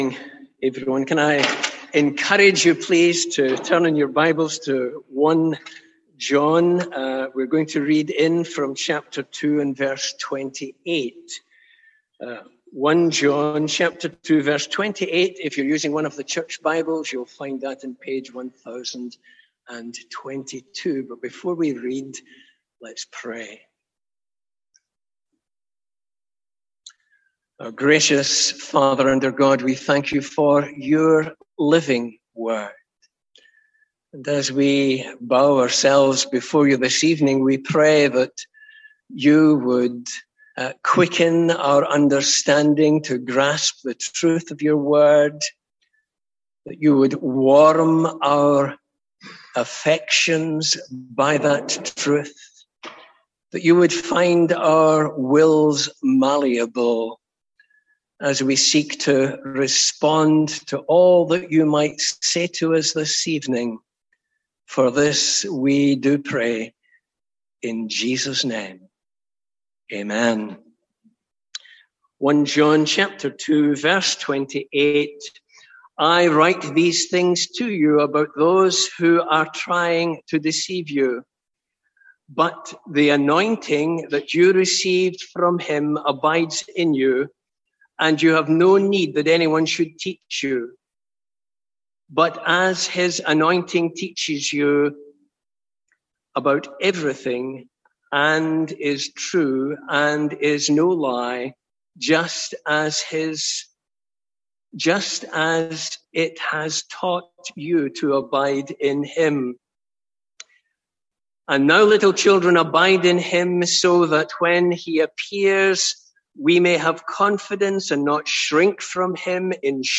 Sermons | St Andrews Free Church
From our evening series in "Calendar Verses"